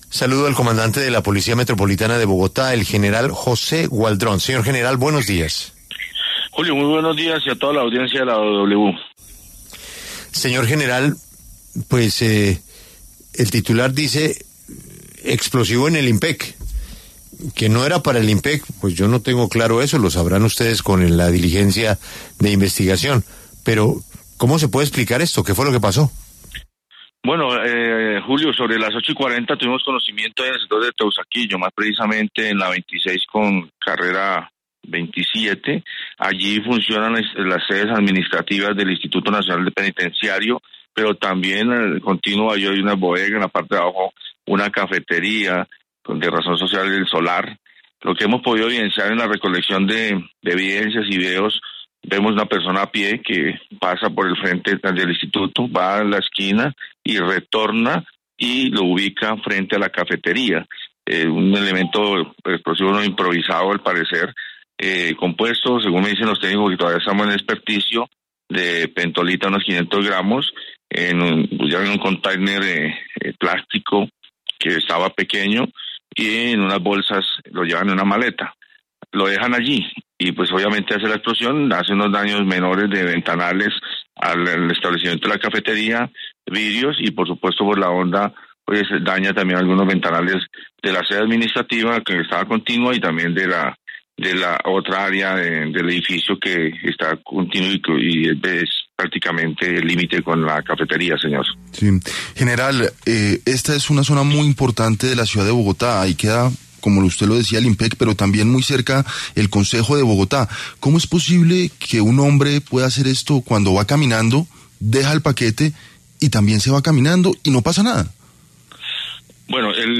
No descartamos ninguna hipótesis: general Gualdrón sobre explosión en Teusaquillo